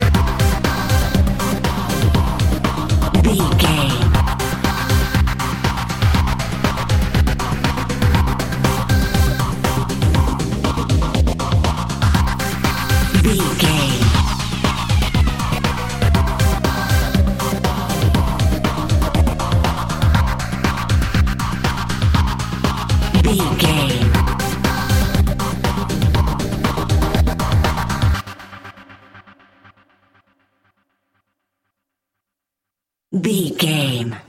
Aeolian/Minor
Fast
drum machine
synthesiser
electric piano
Eurodance